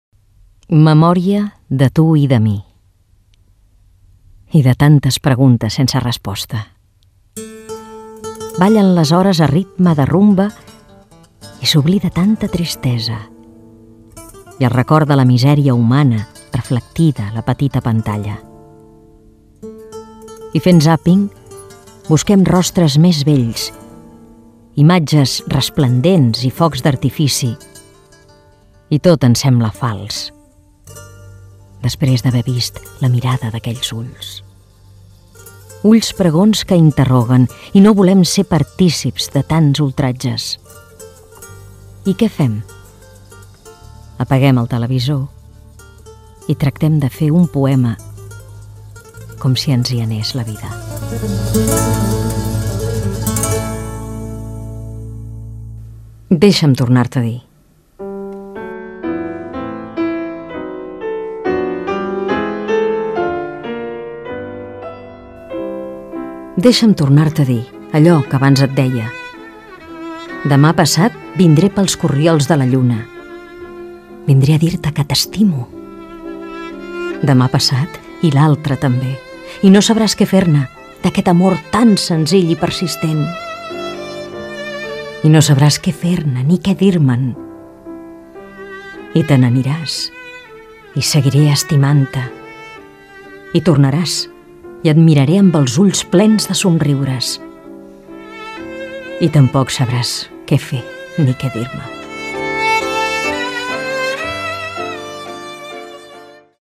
POEMES de Montserrat Abelló